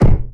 crossbow_hit2.wav